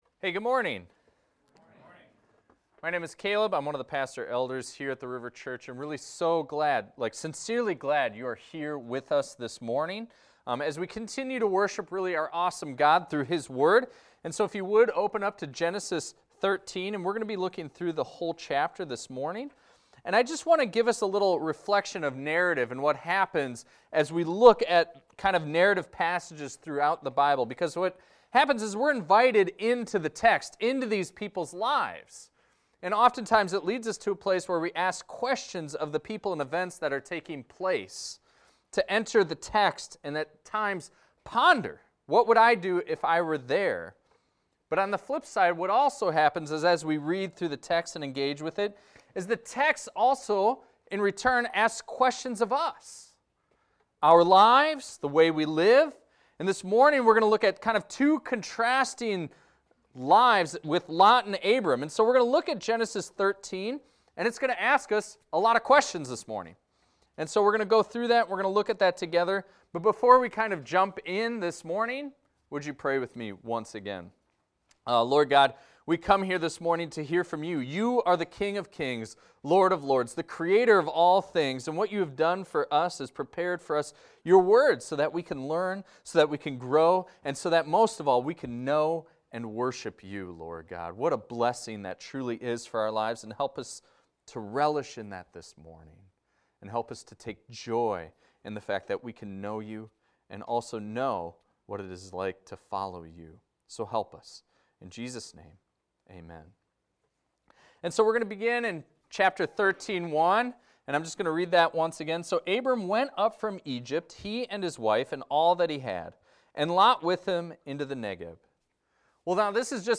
This is a recording of a sermon titled, "Lot's Choice."